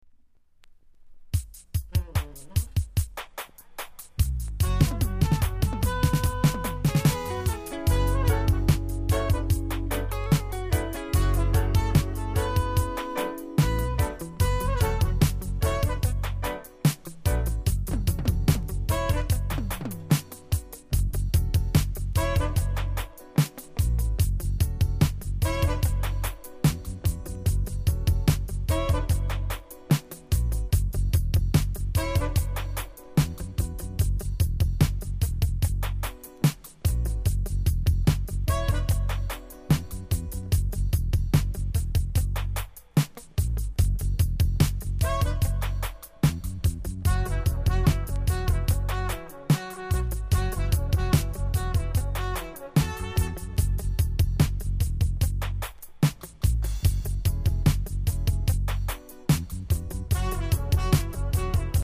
※多少小さなノイズはありますが概ね良好です。